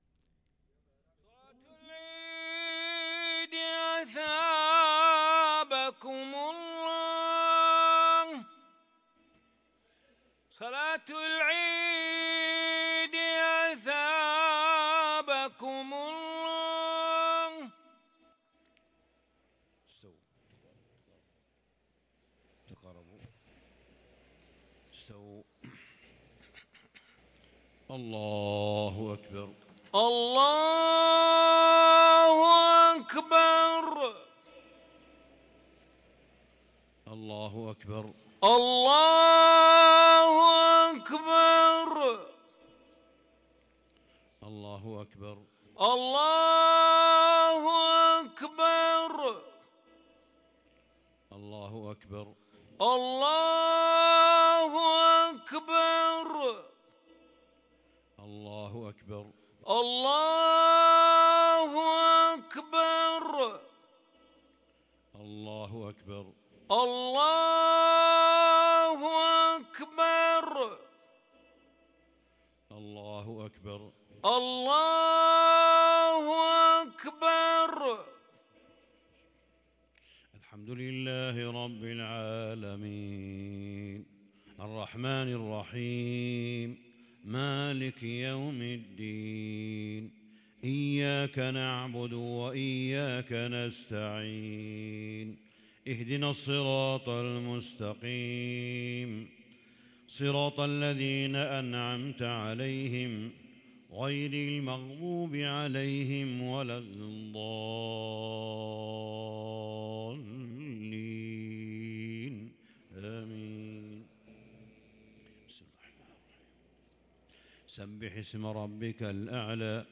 صلاة عيد الفطر سورتي الأعلى والغاشية ١شوال ١٤٤٣هـ | Eid prayers from Surat Al-A'la and Al-Ghashiyah 2-5-2022 > 1443 🕋 > الفروض - تلاوات الحرمين